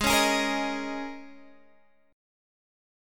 G#sus2sus4 Chord